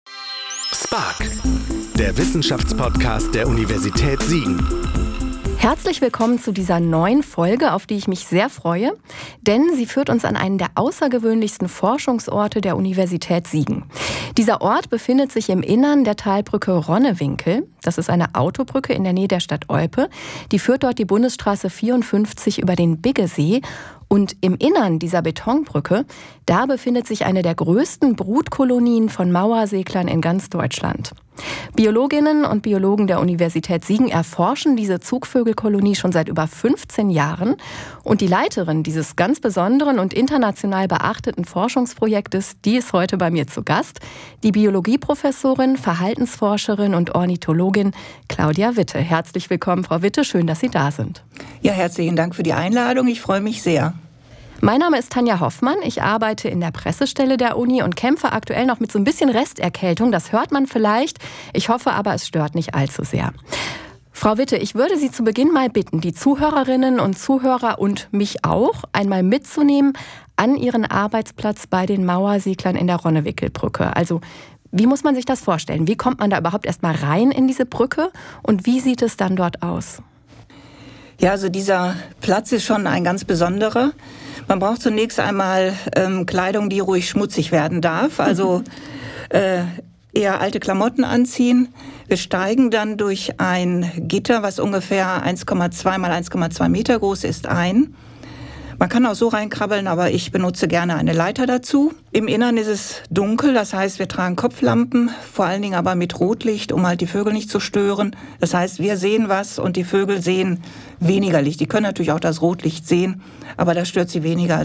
Leben am Limit – Interview